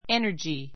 energy 中 énə r dʒi エ ナヂ 名詞 複 energies énə r dʒiz エ ナヂ ズ 元気, （心身の） 力, 精力; 活動力; エネルギー（資源） work with energy work with energy 精力的に働く They put all their energies into helping orphans.